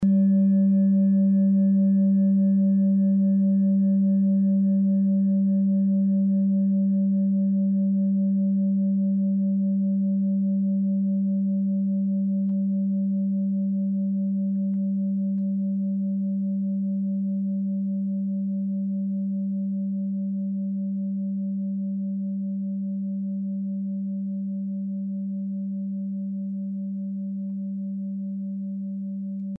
Klangschale TIBET Nr.16
(Ermittelt mit dem Filzklöppel)
klangschale-tibet-16.mp3